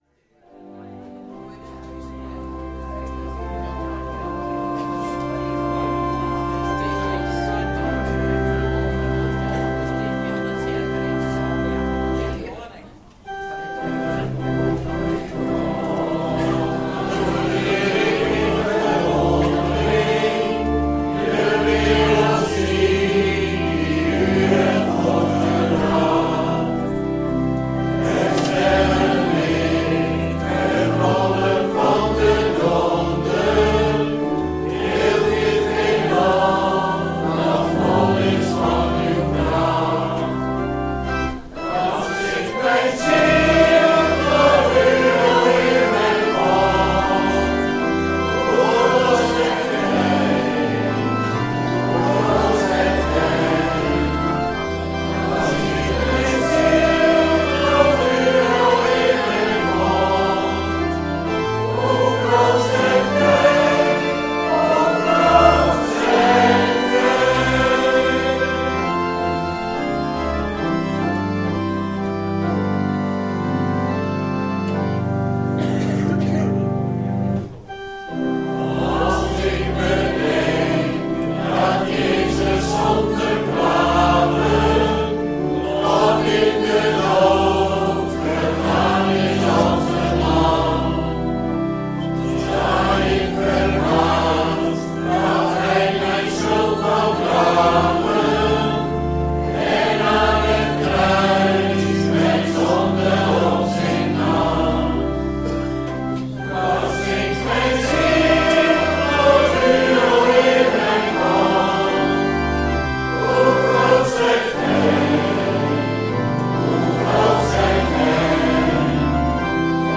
Thema: Verzadigd en nu ?? Categorie: Psalmen Label: Schriftlezing: Psalmen 65